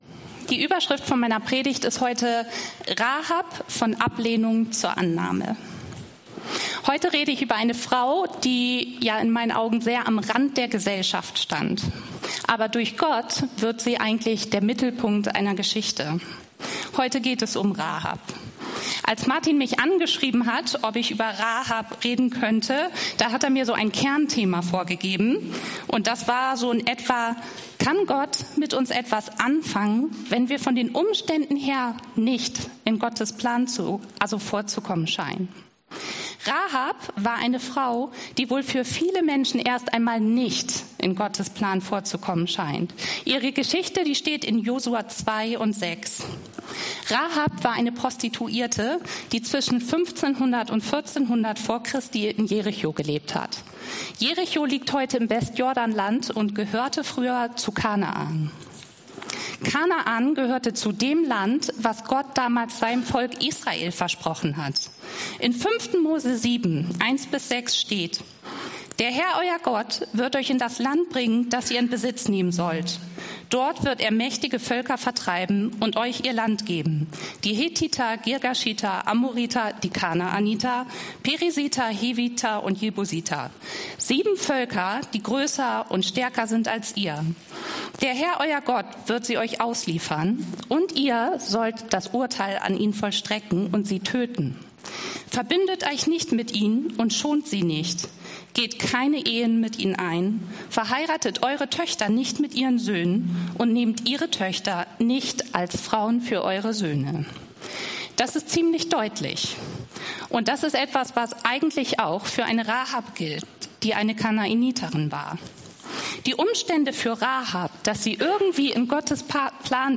wie Gott Geschichte schreibt Dienstart: Predigt « wie Gott mir durch Tamar einen Spiegel vorhält Rut